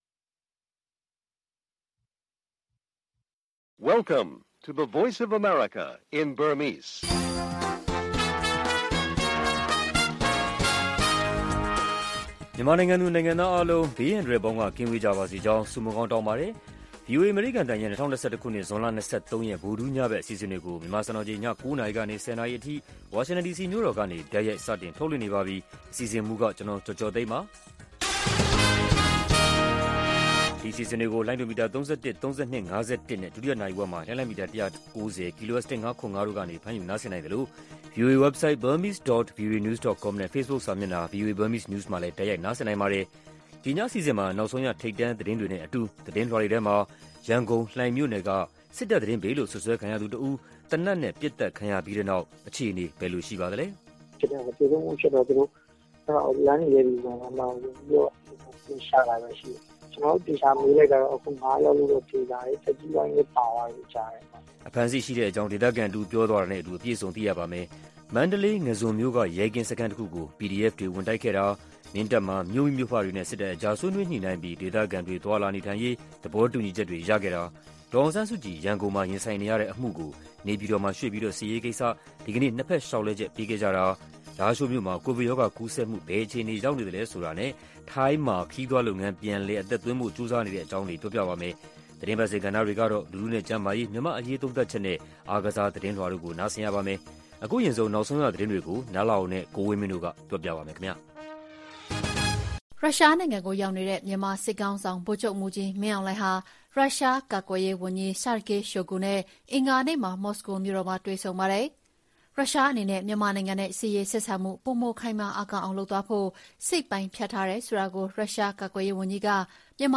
VOA ညပိုင်း ၉း၀၀-၁၀း၀၀ တိုက်ရိုက်ထုတ်လွှင့်မှု